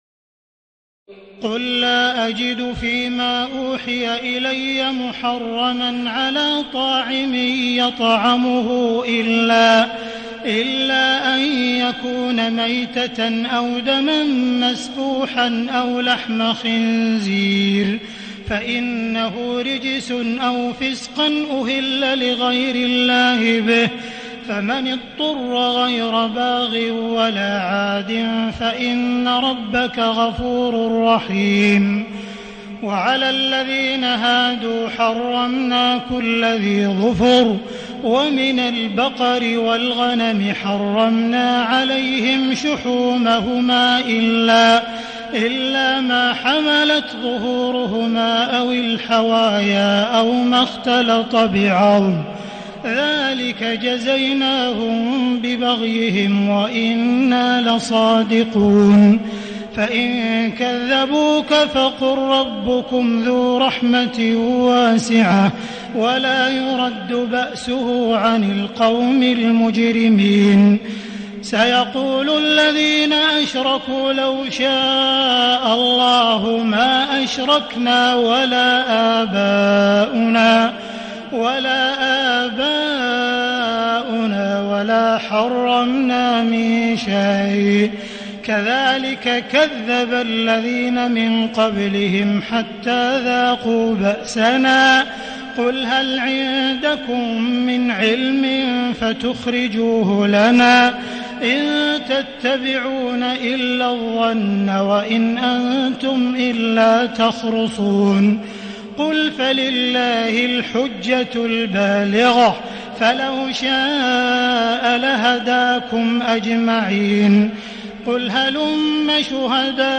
تراويح الليلة السابعة رمضان 1437هـ من سورتي الأنعام (145-165) و الأعراف (1-64) Taraweeh 7 st night Ramadan 1437H from Surah Al-An’aam and Al-A’raf > تراويح الحرم المكي عام 1437 🕋 > التراويح - تلاوات الحرمين